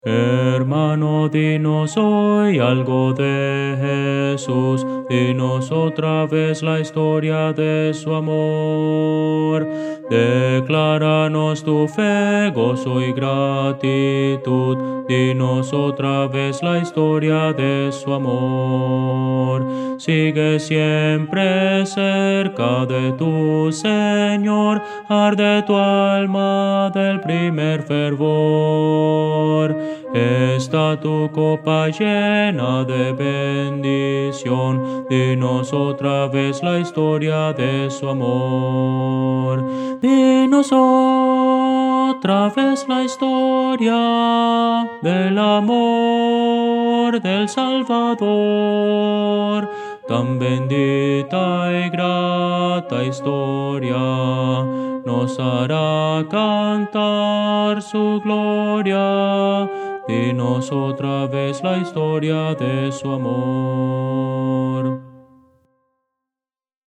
Voces para coro
Contralto